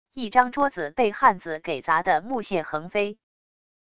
一张桌子被汉子给砸的木屑横飞wav音频